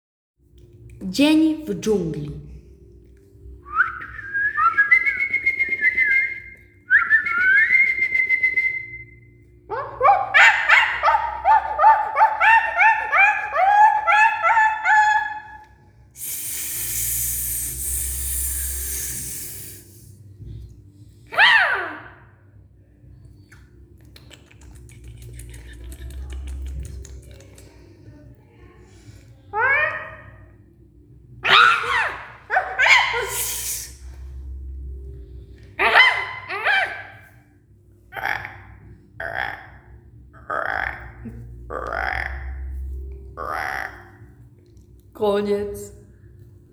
Zabawa z dźwiękami
Grupa zaawansowana oraz młodzieżowa Teatru Szóstka wykonały ćwiczenia polegające na odtworzeniu danego tematu jedynie za pomocą wydawanych przez siebie dźwięków. Dodatkowym utrudnieniem było to że można było używać wyłącznie dźwięków wydawanych własnym głosem.